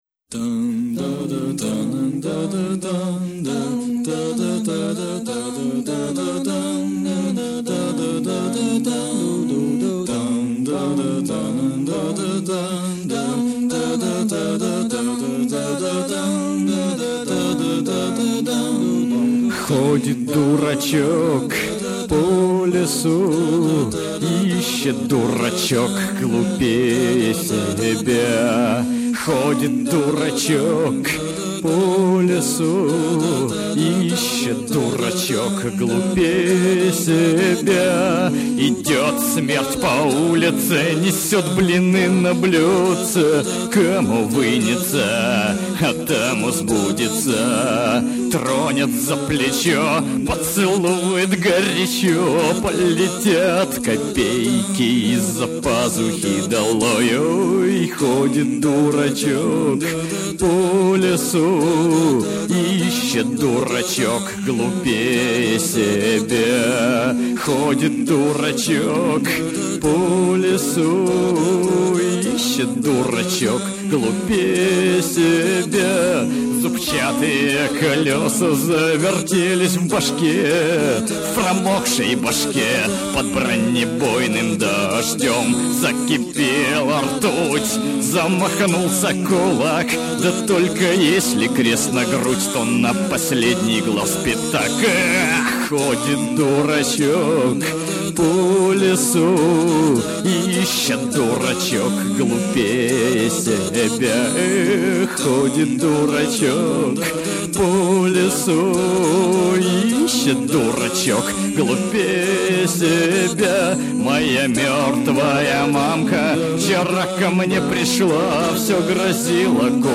ну, она же без сопровождения вообще...
Страшная песня получилась, говорю же.